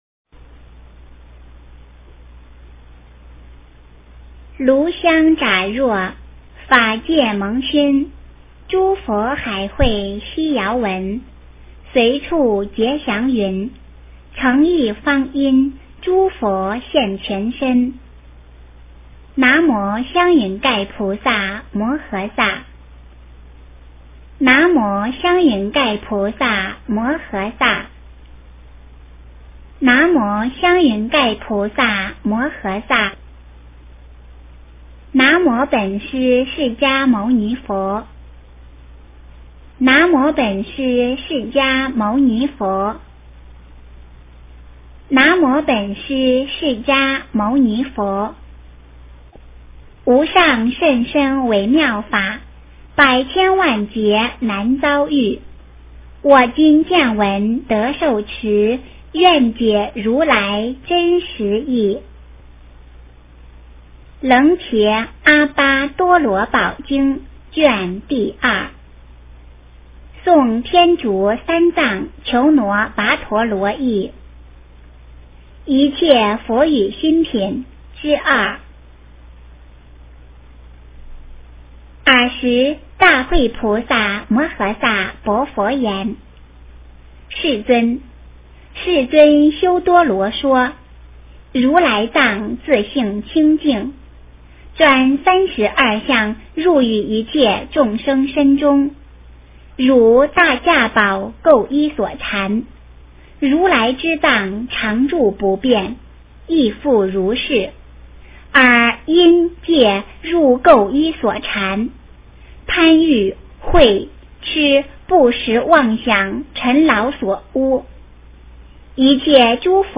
楞伽阿跋多罗宝经2 - 诵经 - 云佛论坛